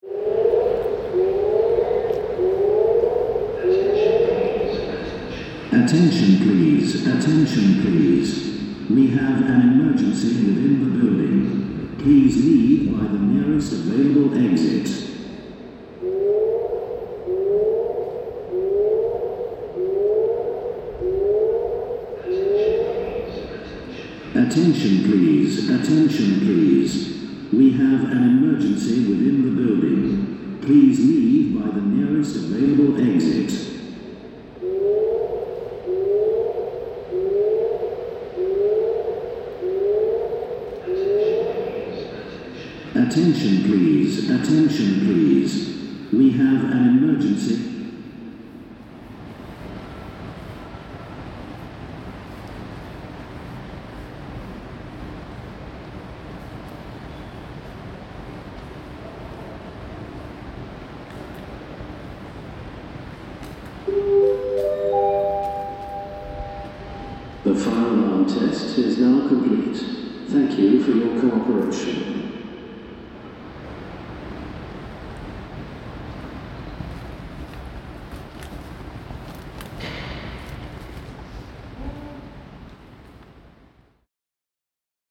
The sound of the emergency alarm being tested in an empty shopping centre - shades of post-apocalyptic movies or Dawn of the Dead, as the emergency announcement echoes out across the cavernous space, which is deserted this early in the morning.
Recorded in Oxford, UK in November 2025 by Cities and Memory.